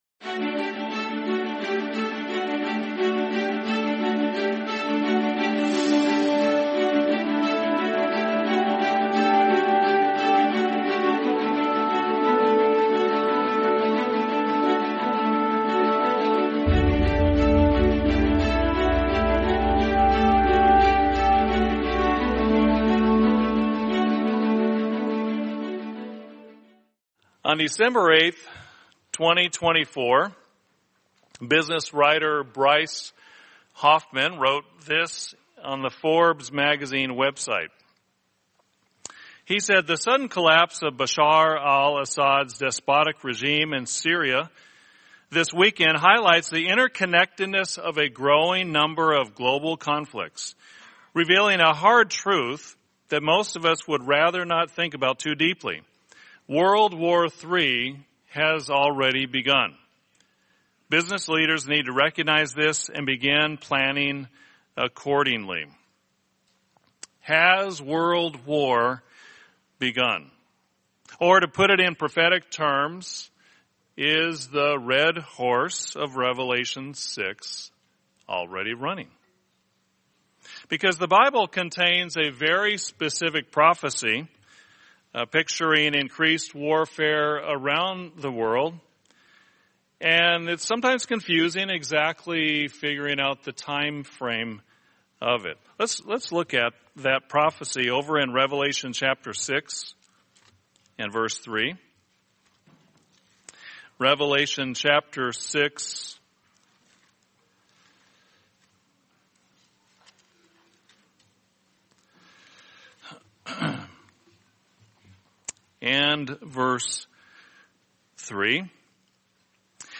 Sermon The Red Horse of Revelation